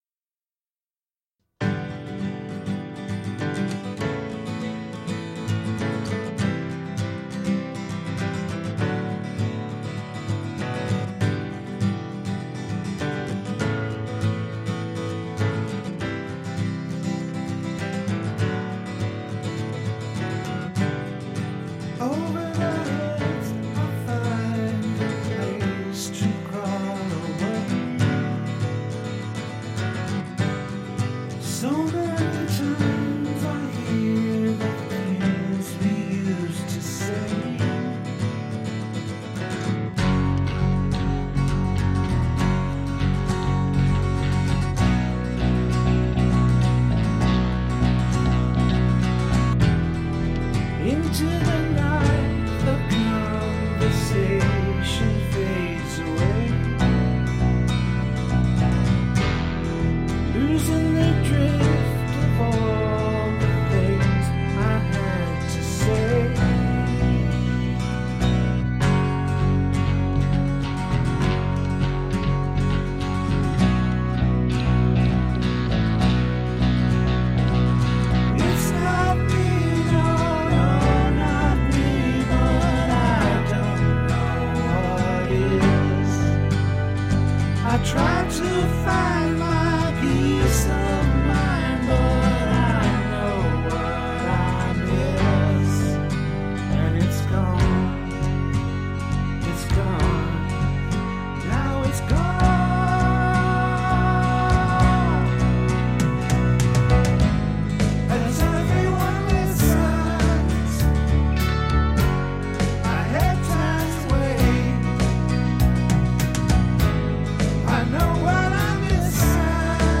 - Classic Rock Covers -